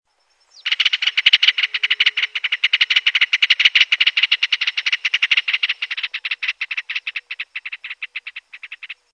martim-pescador e voando do aeroporto para o morro do Matarazzo sempre passa um
Todos sons do centro da cidade de Ubatuba.